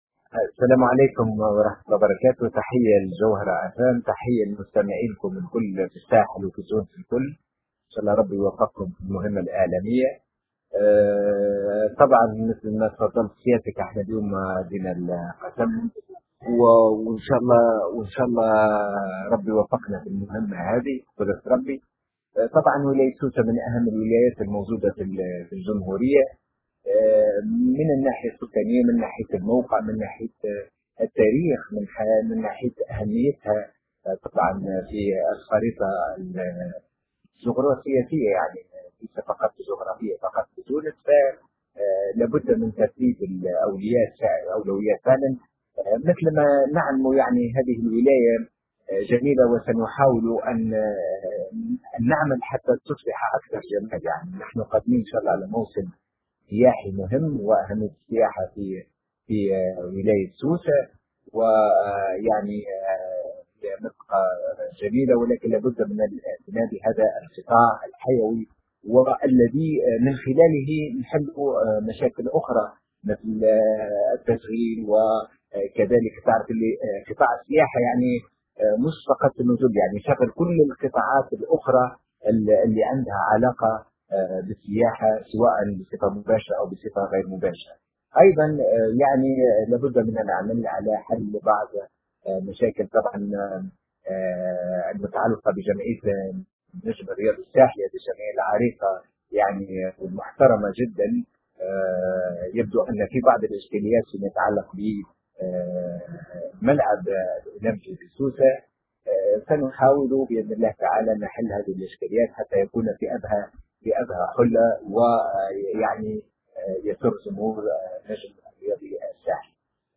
والي سوسة الجديد يتحدث للجوهرة أف أم عن أبرز أولوياته.. وللنجم الساحلي نصيب